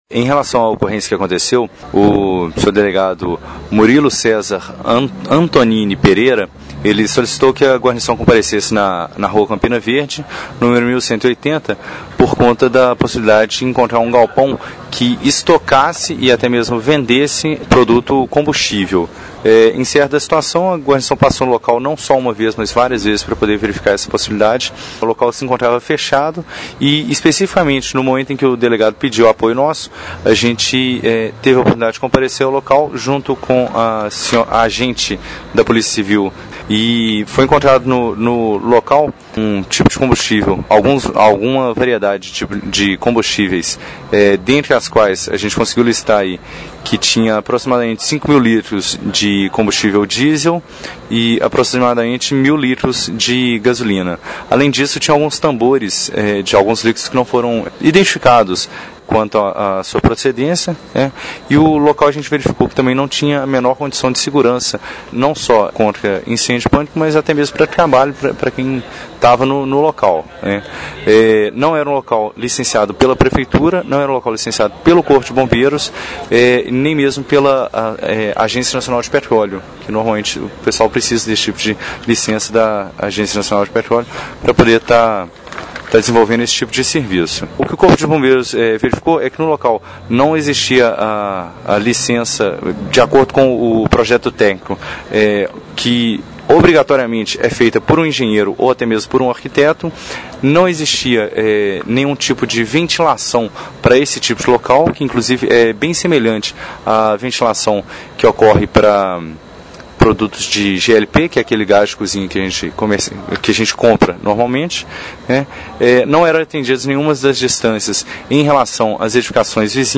A descoberta de um barracão que funcionava como posto de abastecimento clandestino no início desta semana resultou em uma ação que foi desenvolvida pelo Corpo de Bombeiros para fiscalizar locais de risco. (Clique no player abaixo e ouça a entrevista).